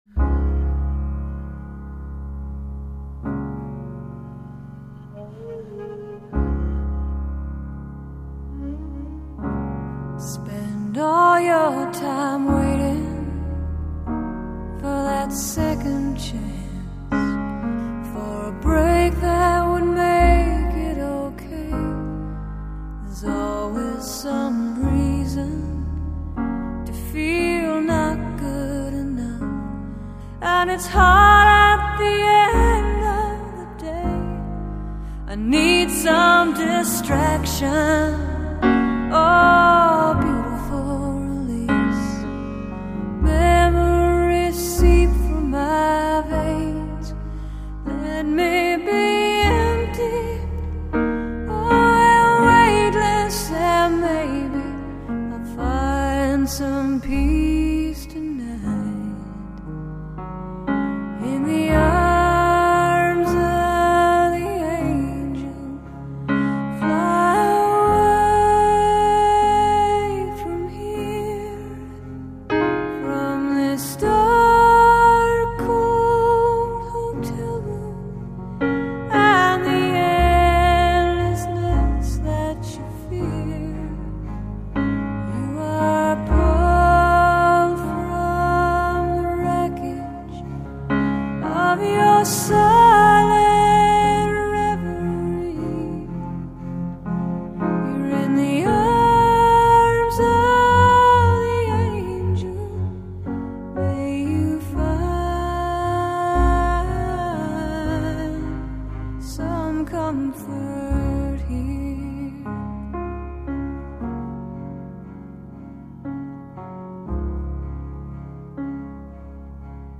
她的唱腔中带着标志性的鼻音，轻柔地，舒缓地衬托出一种难以言传的味道